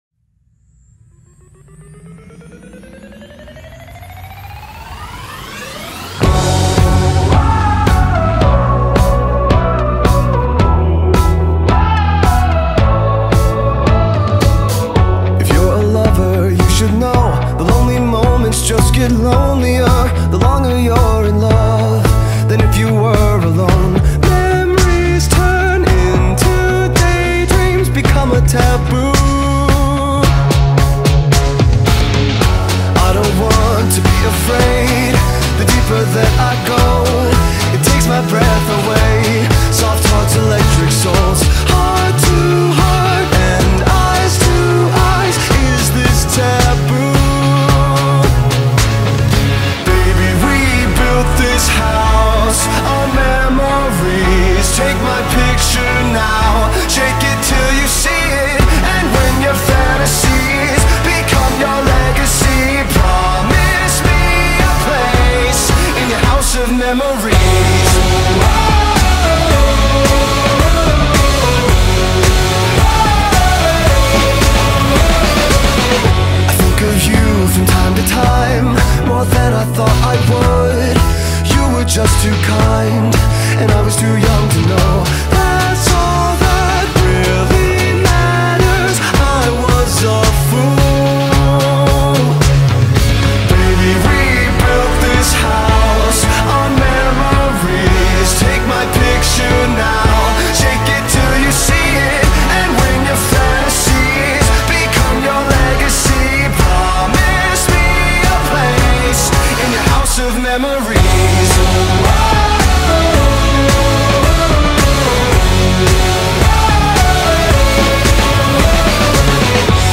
Alternative Pop / Rock